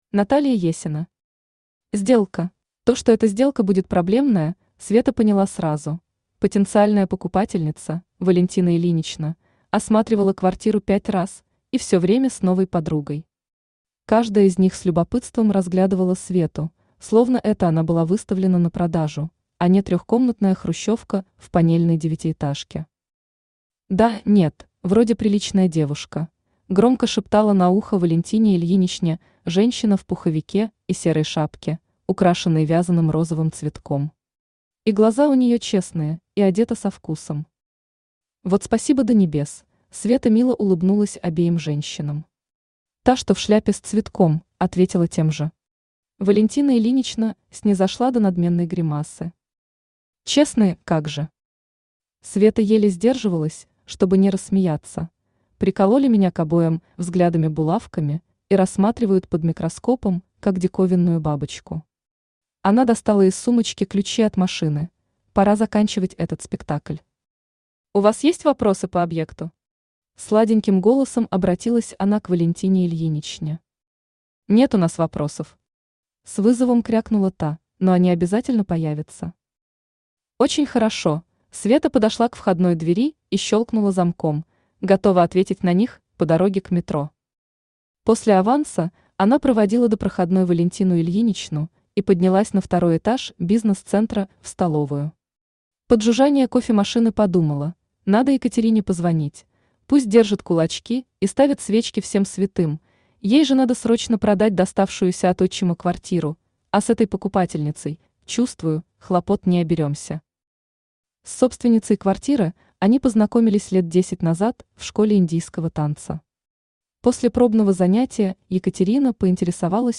Aудиокнига Сделка Автор Наталья Есина Читает аудиокнигу Авточтец ЛитРес. Прослушать и бесплатно скачать фрагмент аудиокниги